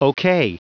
Prononciation du mot okay en anglais (fichier audio)
Prononciation du mot : okay